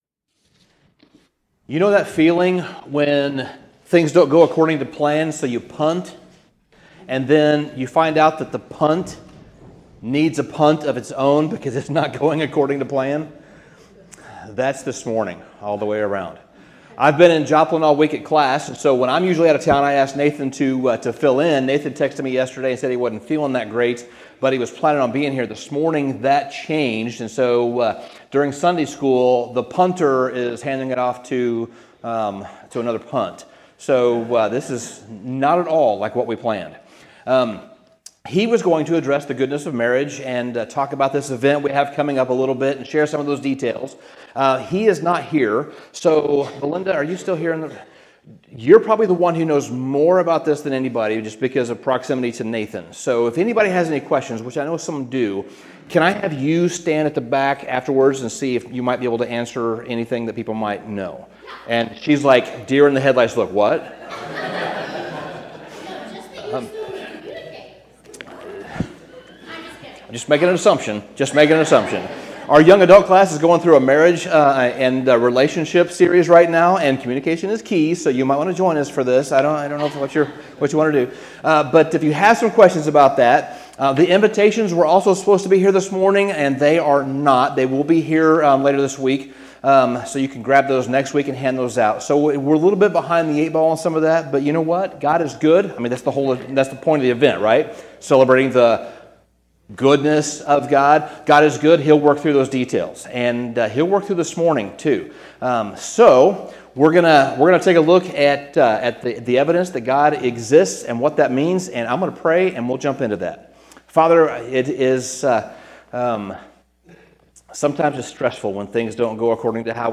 Feb 03, 2025 The Evidence For God MP3 SUBSCRIBE on iTunes(Podcast) Notes Sermon Summary Sometimes, things don't always go according to plan.